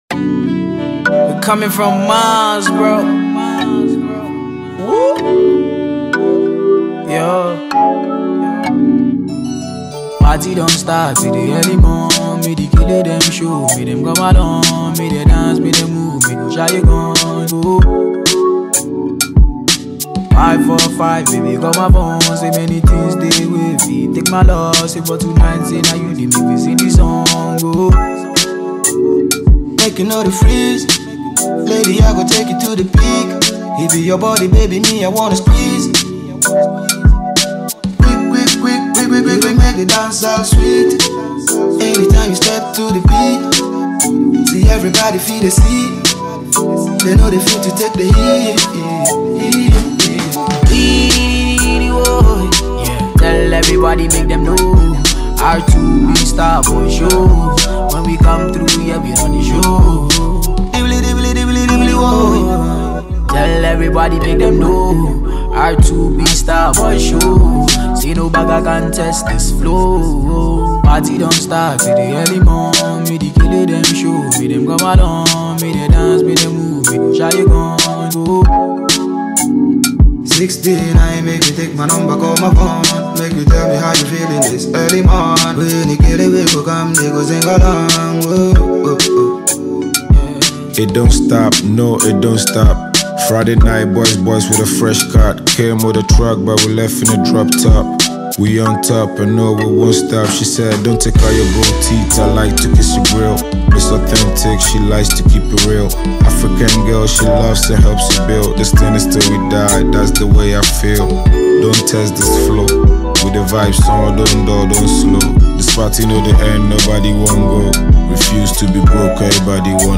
Ghanaian Award-winning duo
mid-tempo jam